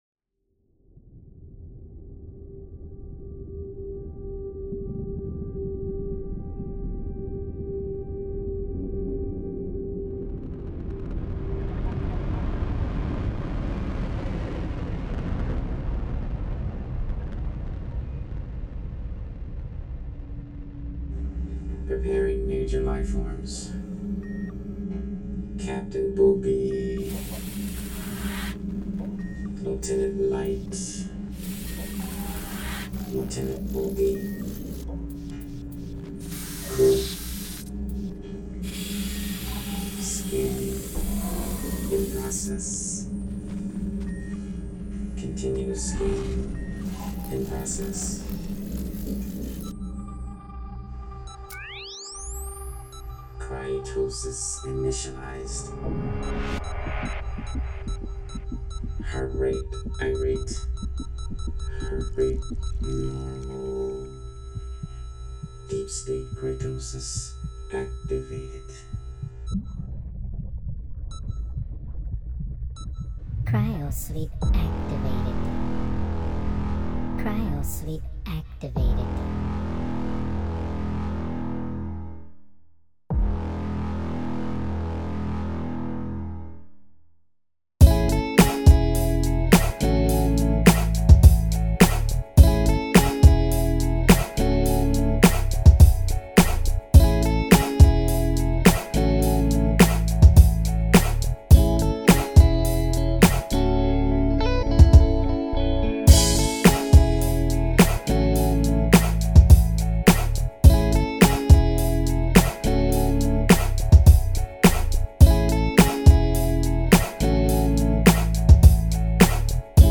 Soulful groove with rock infused.